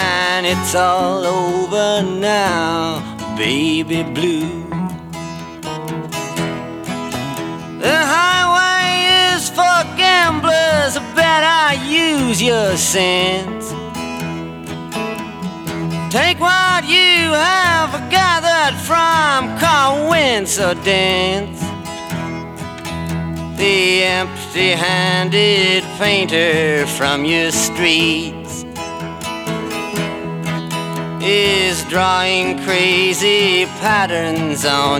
Folk-Rock
Жанр: Рок / Фолк